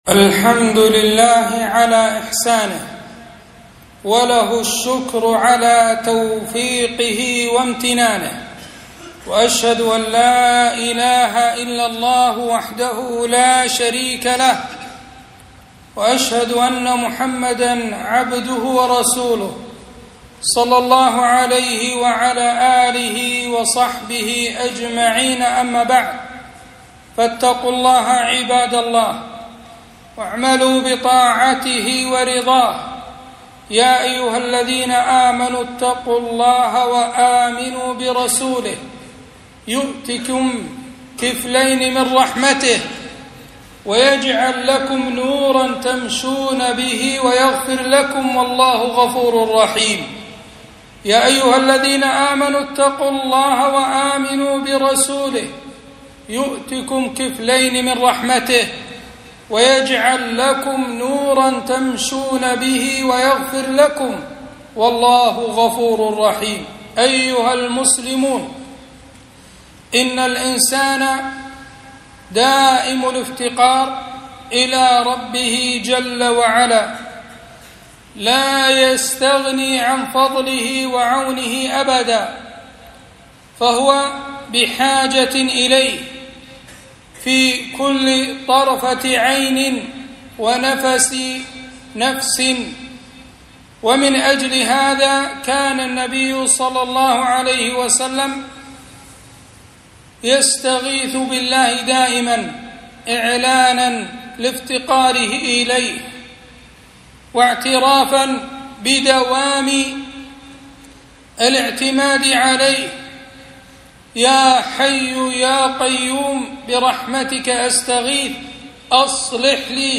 خطبة - ( وما توفيقي إلا بالله )